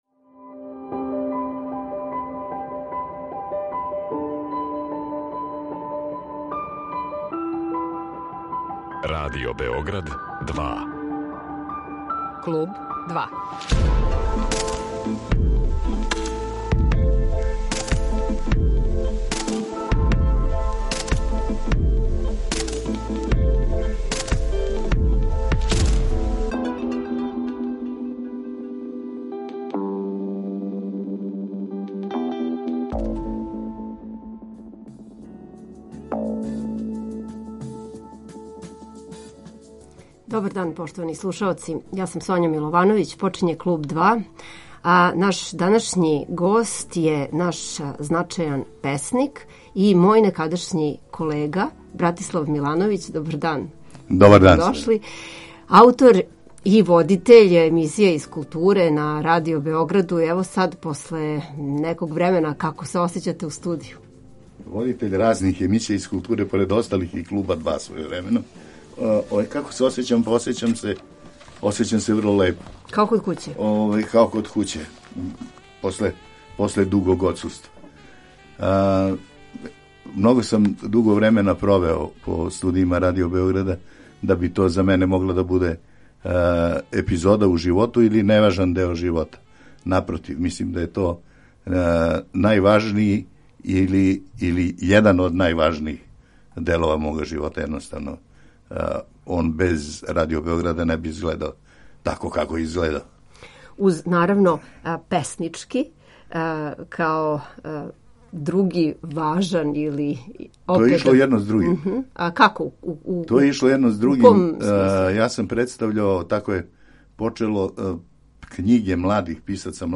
Поменута књижевна одличја биће такође тема нашег данашњег сусрета у Студију 2 Радио Београда.